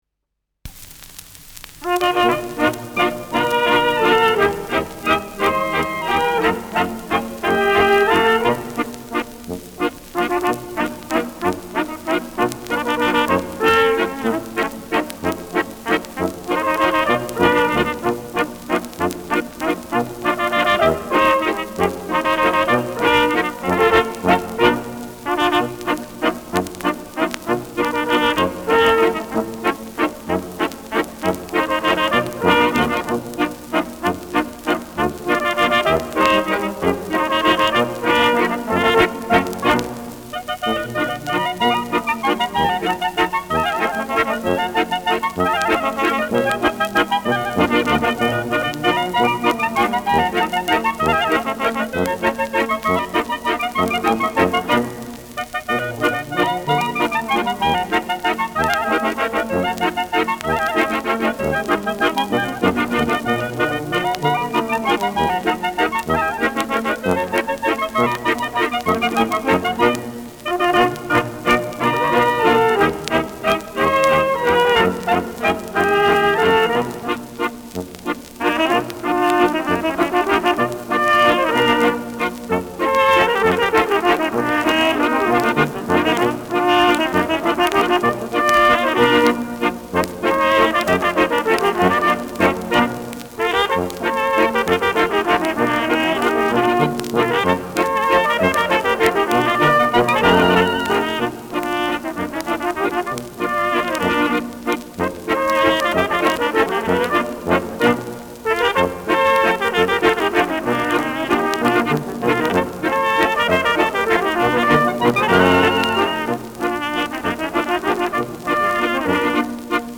Schellackplatte
leichtes Knistern